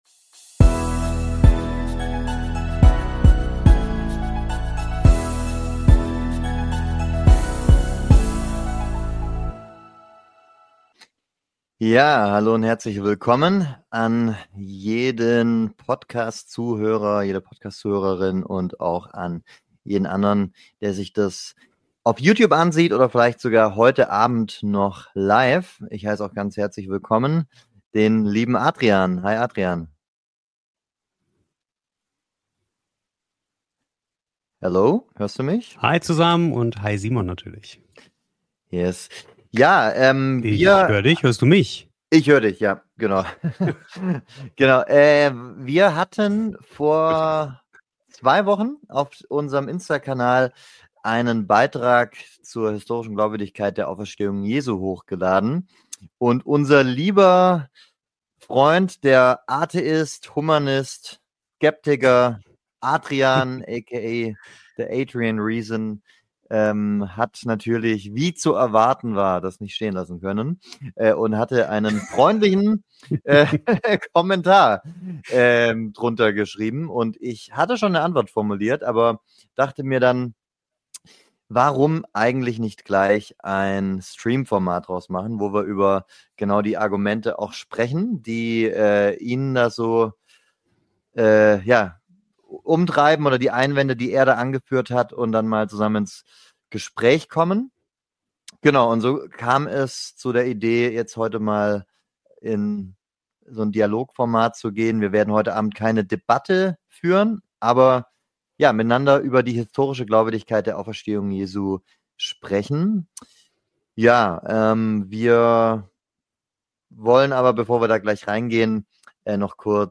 Ist Jesus auferstanden? Atheist und Christ im Dialog ~ Apologetik Projekt Podcast